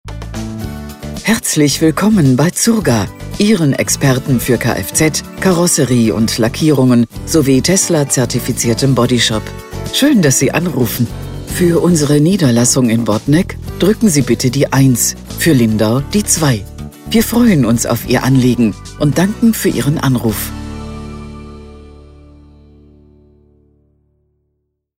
Telefonansagen mit echten Stimmen – keine KI !!!
IVR Ansage
Zurga-IVR.mp3